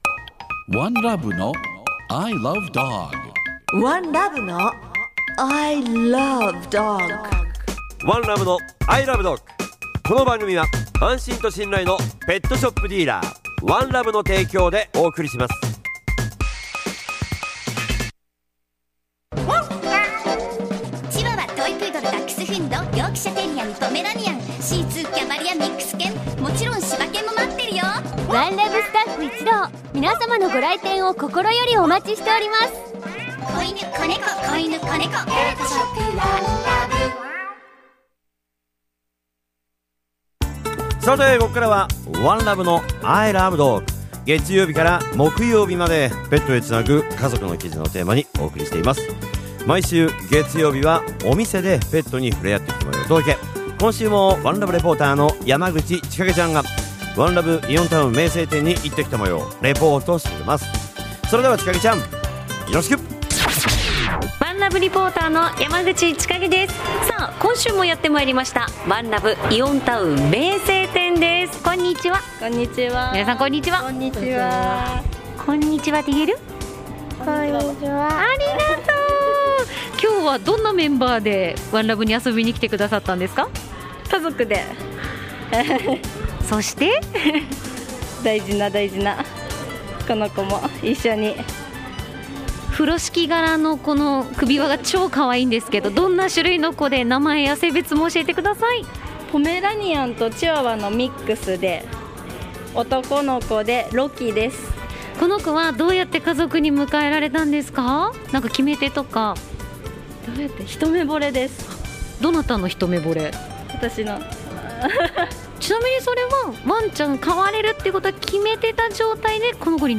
月曜は、街角突撃インタビューが聞けるワン！